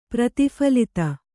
♪ prati phalita